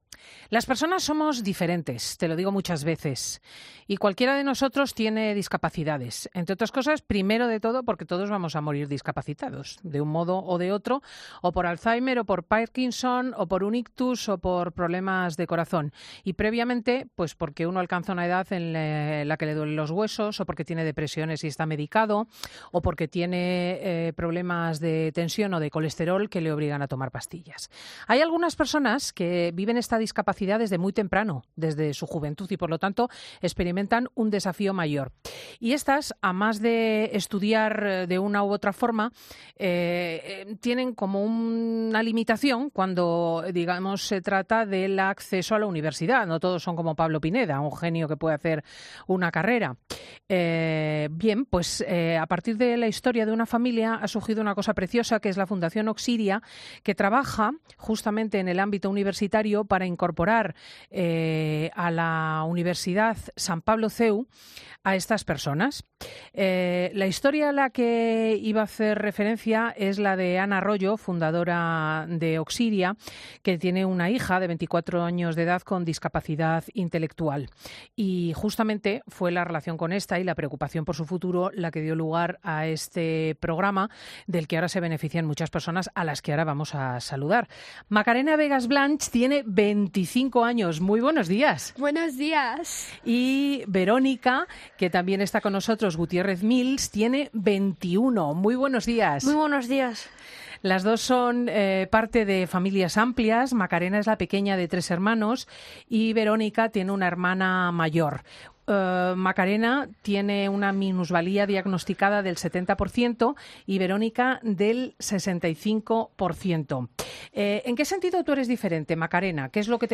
Entrevista 'Fundación Oxiria'